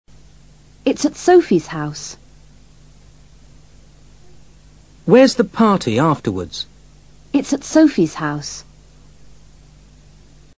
Cuando deseamos enfatizar una palabra en una oración, decimos esa palabra en voz más elevada que lo normal (more loudly) y también lo hacemos utilizando un tono más alto (a higher pitch).